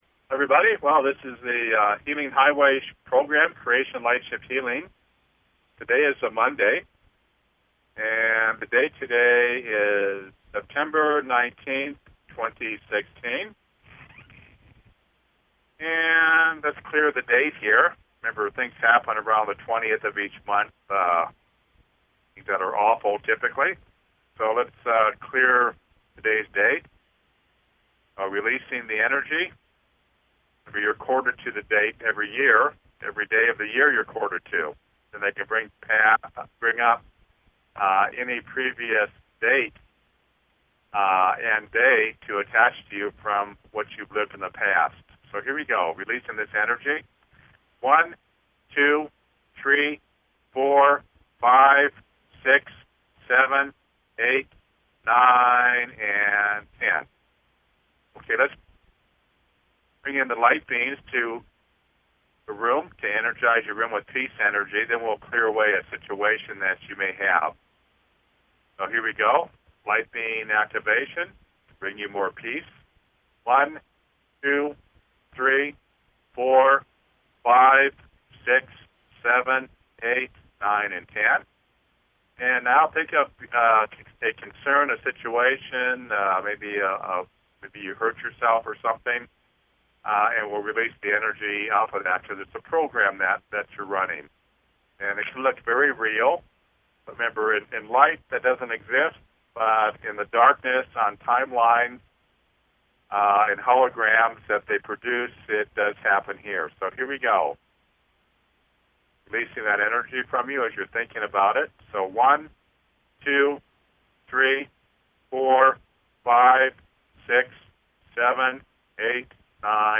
Talk Show Episode, Audio Podcast, Creation Lightship Healings